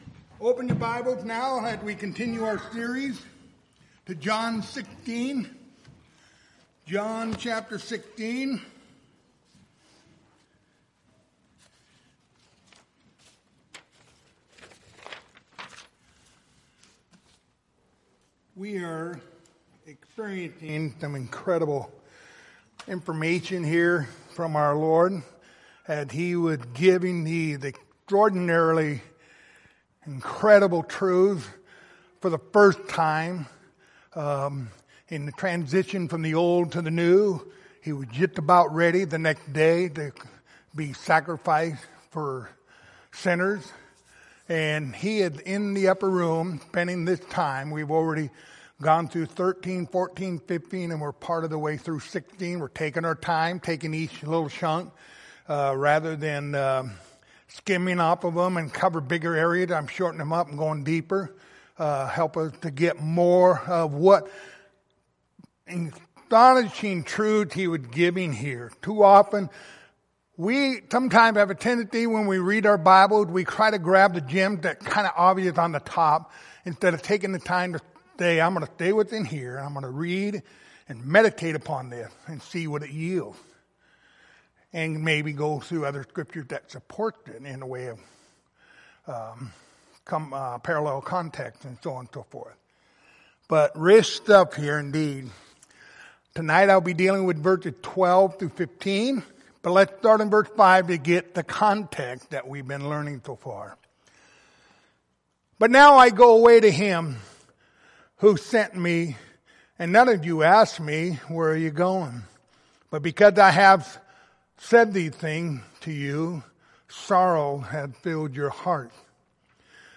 Passage: John 16:12-15 Service Type: Wednesday Evening